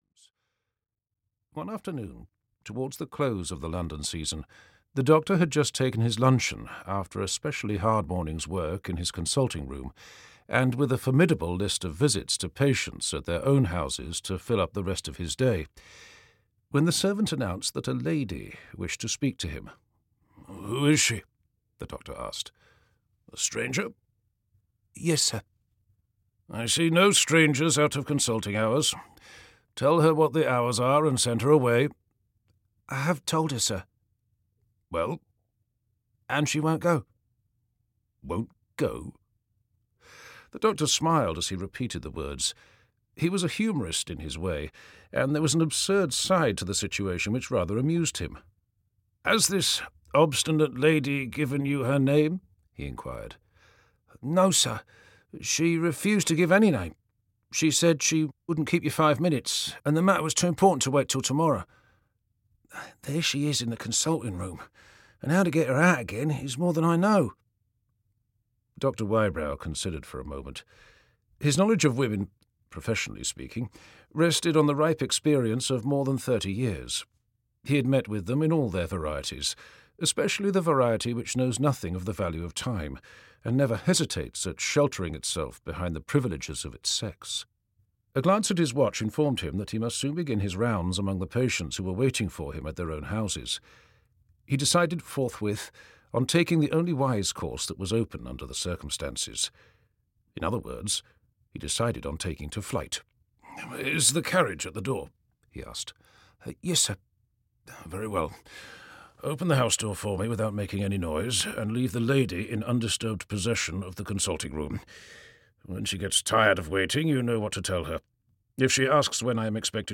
The Haunted Hotel (EN) audiokniha
Ukázka z knihy